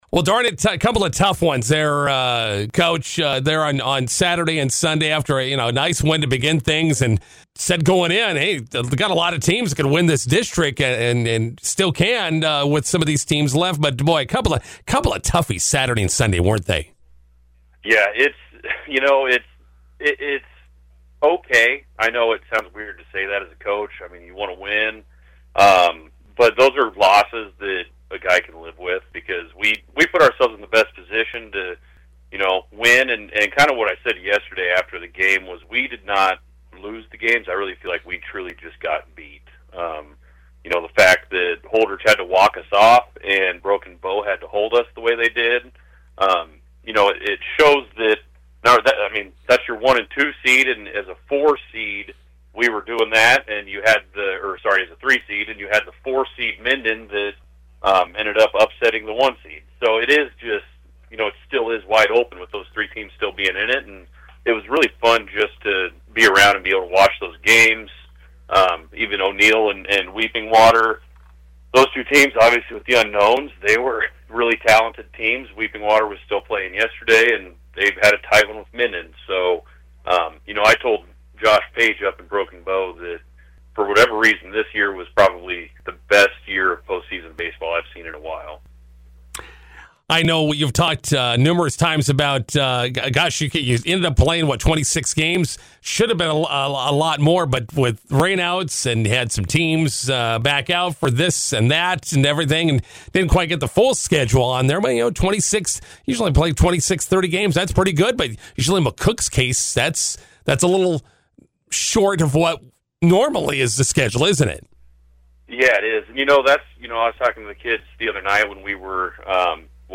INTERVIEW: MNB Bank Seniors fall short of district championship game appearance.